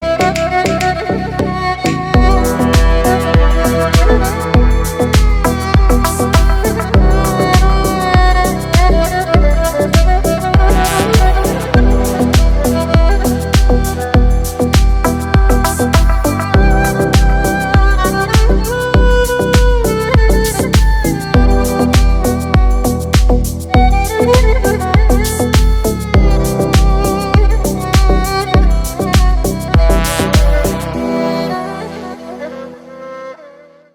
• Качество: 320, Stereo
громкие
deep house
без слов
красивая мелодия
восточные
Классная арабская музыка